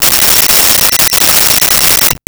Cell Phone Ring 04
Cell Phone Ring 04.wav